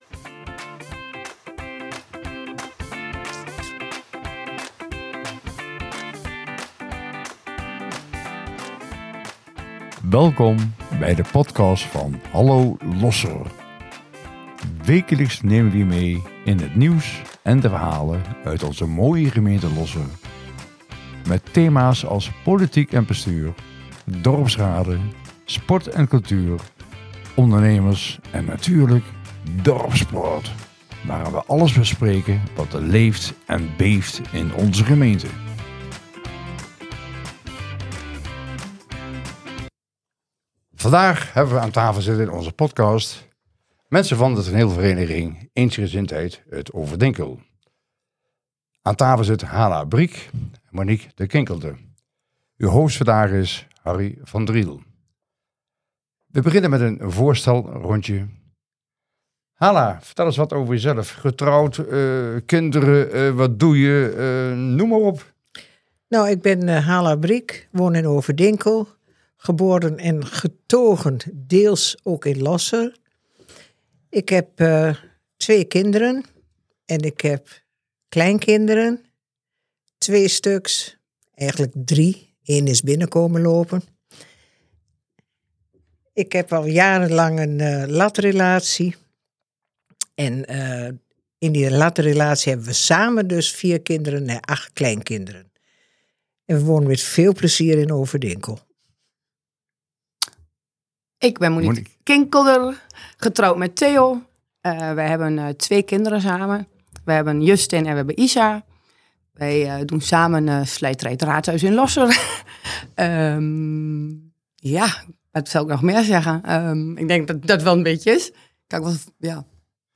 Ze vertellen over het belang van hun vereniging, het behoud van hun theaterlocatie en hoe zij cultuur toegankelijk maken voor jong en oud. Een gesprek vol lokale betrokkenheid, samenwerking en toekomstplannen in Overdinkel.